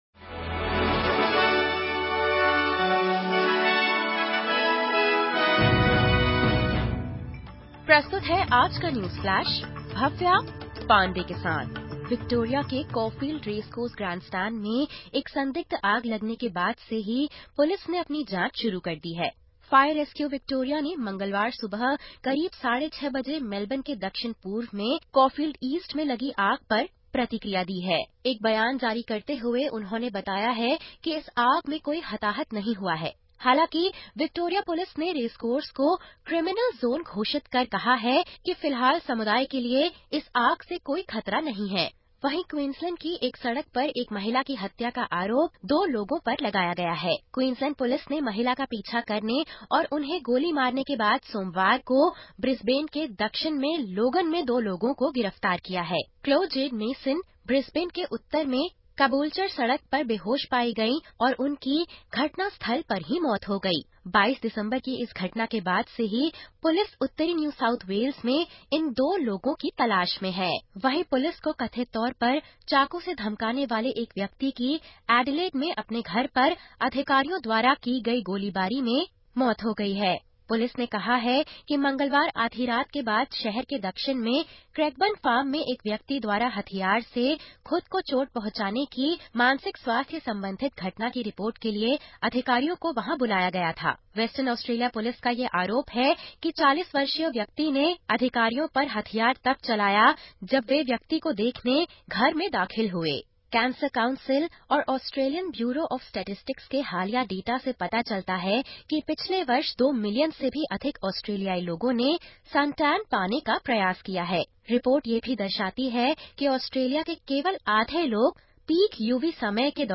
सुनें 07/01/2025 की प्रमुख खबरें ऑस्ट्रेलिया और भारत से हिन्दी में।